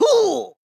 backdamage_flying.wav